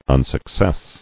[un·suc·cess]